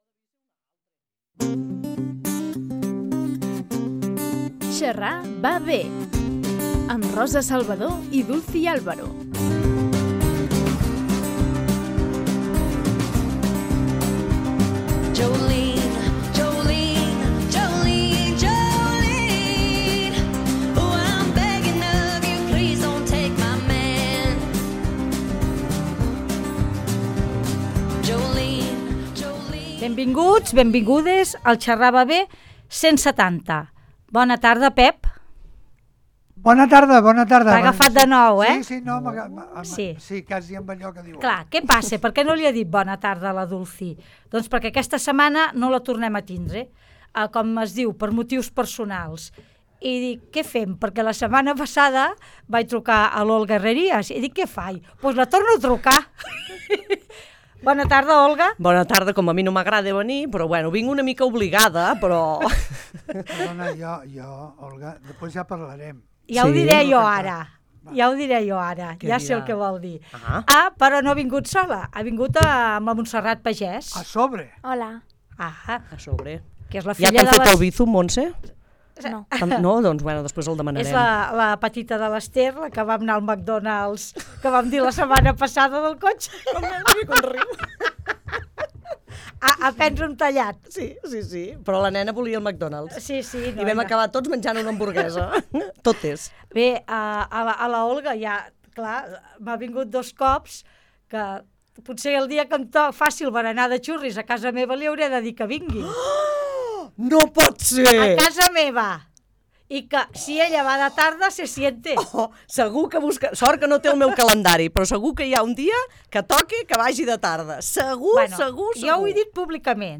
Ens tornem a trobar als estudis de l’emissora per xerrar durant una hora. Partirem d’un tema de conversa, però ja sabeu que anirem obrint altres melons, esperem no prendre mal.